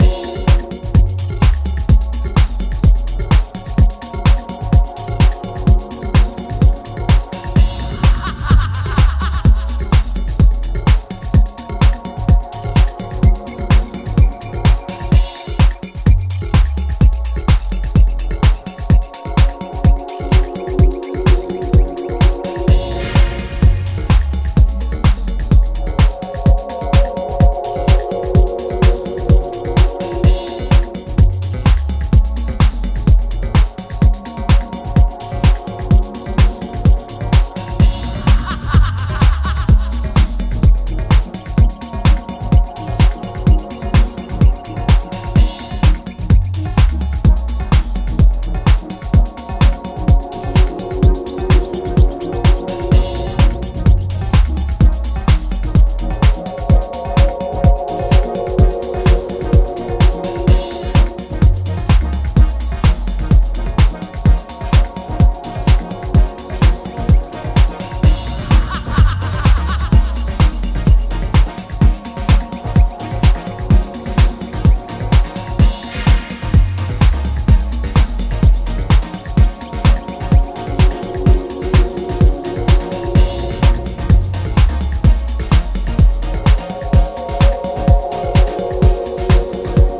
ミニマルでソリッドにシェイプされたタイムレスな魅力溢れるディープ・ハウスの逸品がずらり揃った、素晴らしい好内容盤です！
ジャンル(スタイル) DEEP HOUSE